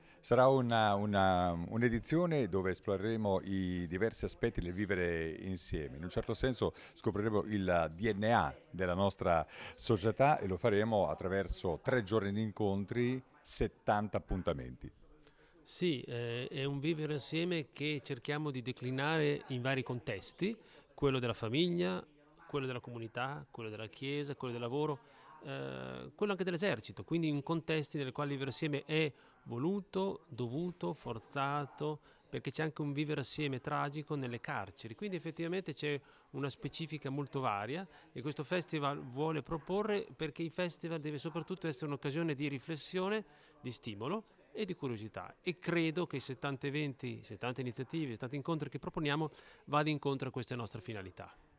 Ecco le dichiarazioni raccolte durante la giornata di presentazione: